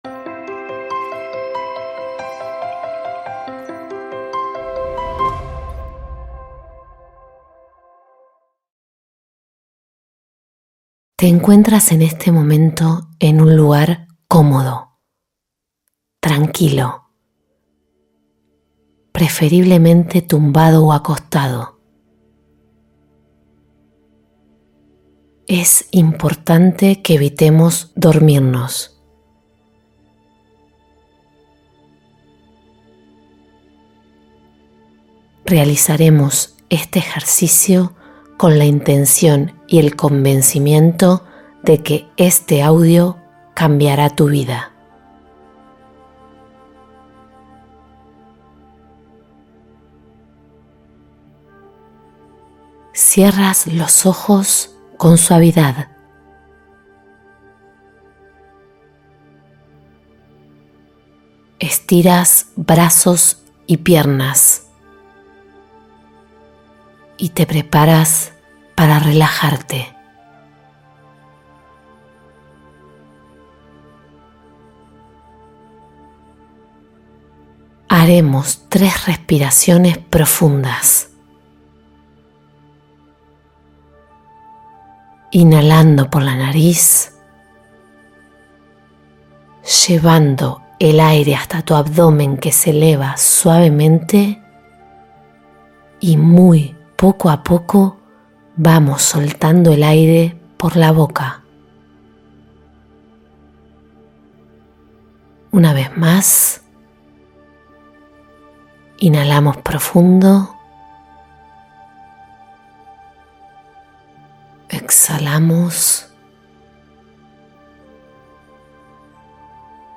Supera miedos e inseguridades con esta meditación guiada de 10 minutos